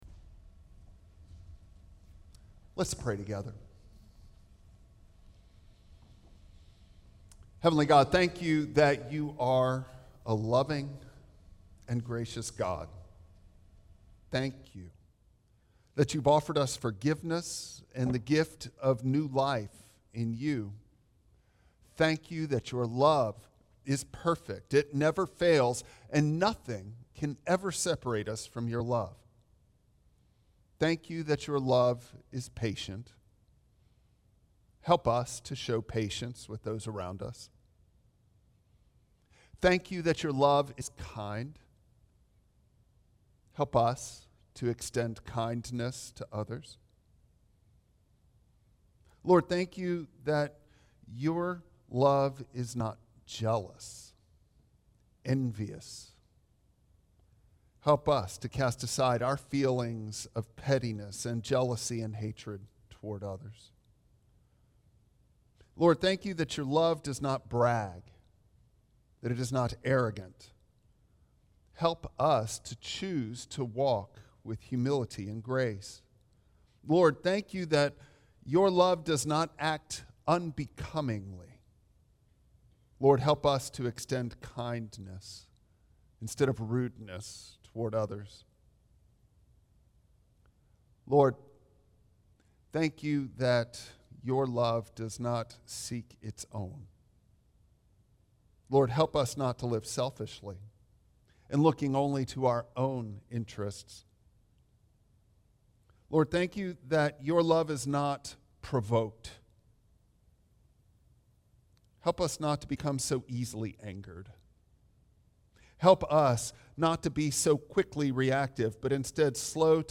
1 Corinthians 13:1-7 Service Type: Traditional Service Bible Text